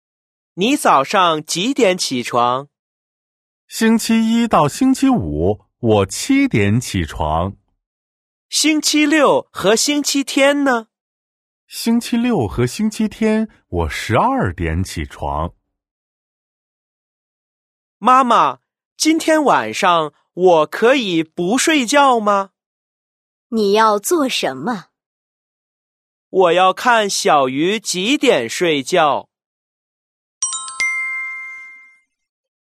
Sau khi đã nắm vững từ vựng, chúng mình hãy vận dụng từ mới vừa học và đọc hai đoạn hội thoại dưới đây nhé: 💿 02-02